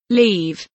leave kelimesinin anlamı, resimli anlatımı ve sesli okunuşu